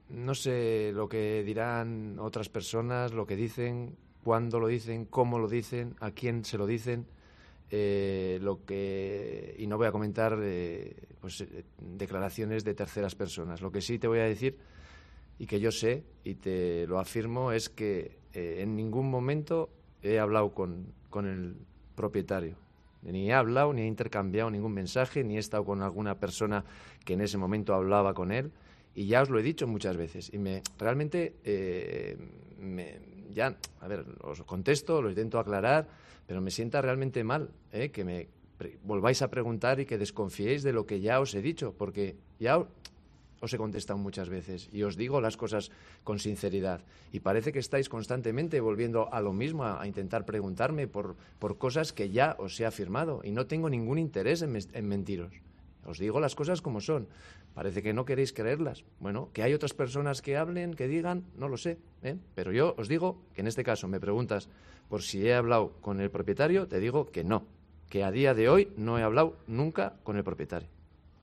El técnico desmiente a su agencia de representación e insiste, enfadado, en que no conoce al propietario del club, ni siquiera por mensaje